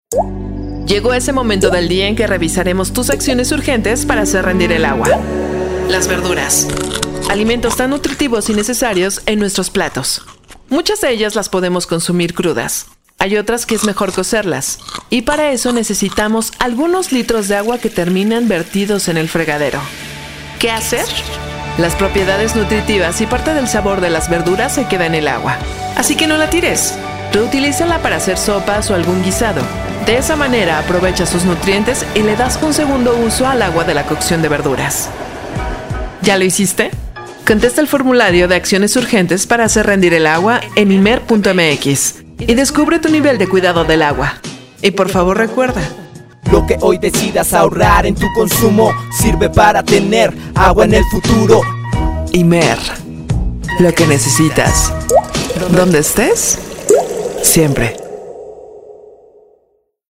ACTUACIÓN DRAMÁTICA